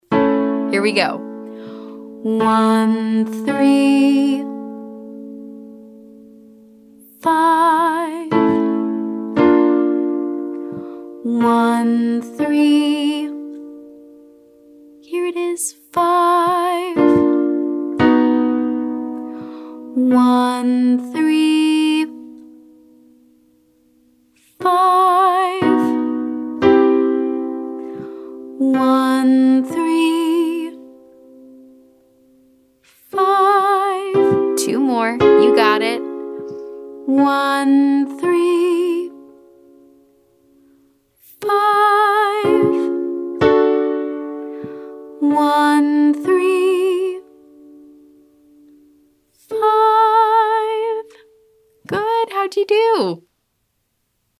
For example, I’ll play 1 & 3, you sing 1, 3, and fill in 5
Exercise/game: Play 13, student sing 135